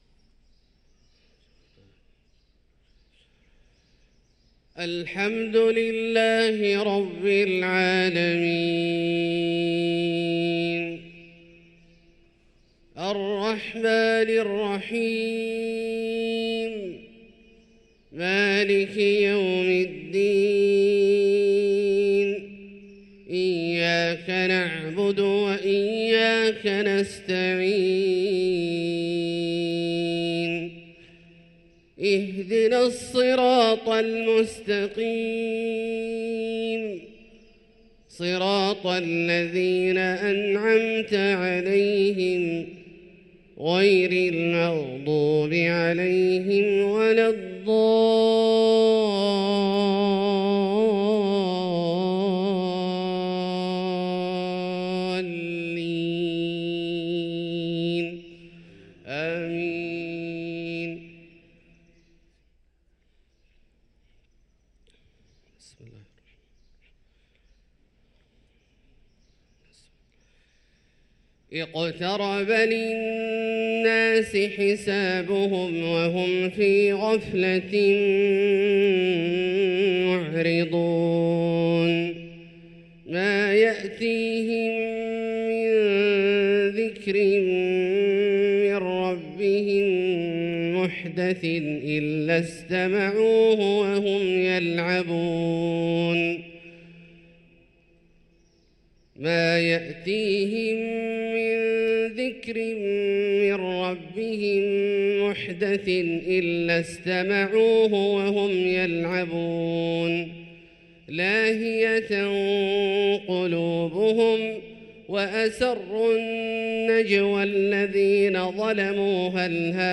صلاة الفجر للقارئ عبدالله الجهني 19 جمادي الآخر 1445 هـ
تِلَاوَات الْحَرَمَيْن .